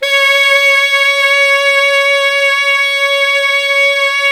Index of /90_sSampleCDs/Roland LCDP07 Super Sax/SAX_Sax Ensemble/SAX_Sax Sect Ens
SAX 2 ALTO08.wav